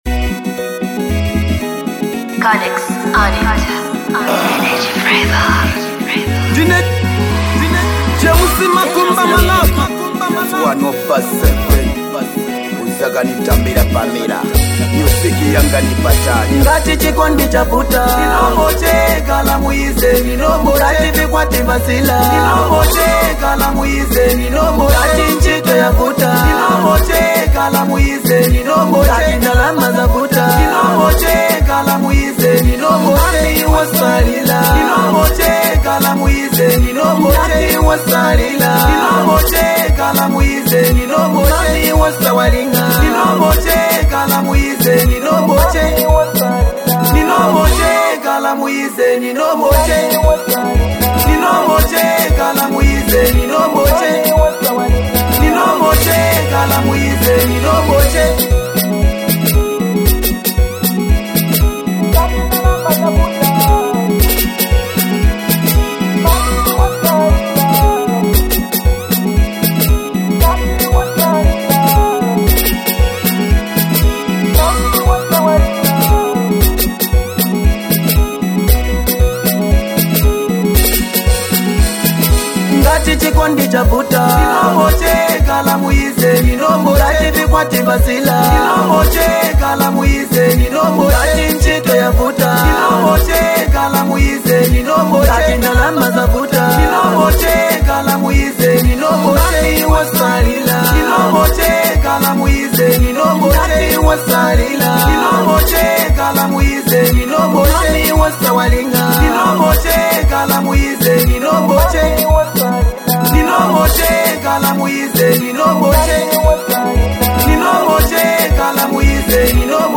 a relatable anthem